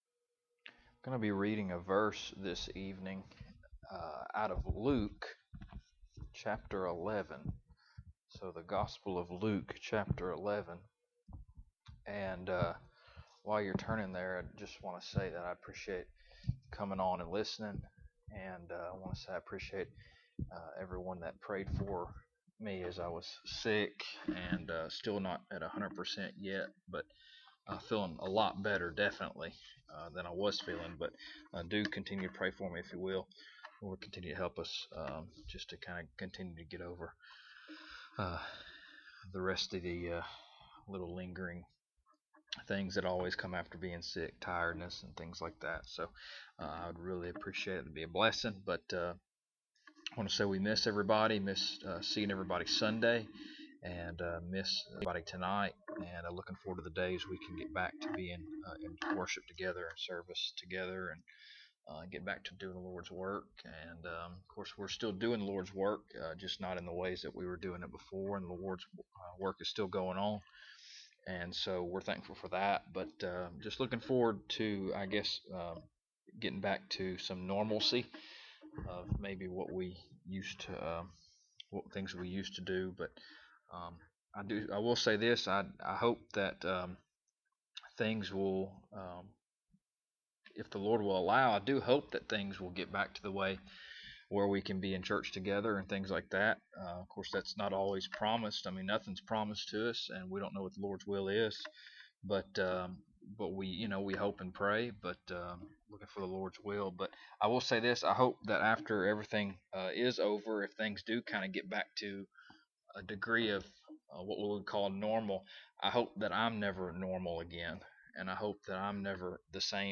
Luke 11:1 Service Type: Wednesday Evening Bible Text